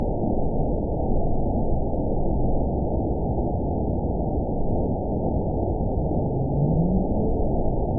event 915158 date 11/21/22 time 07:55:18 GMT (2 years, 7 months ago) score 9.47 location INACTIVE detected by nrw target species NRW annotations +NRW Spectrogram: Frequency (kHz) vs. Time (s) audio not available .wav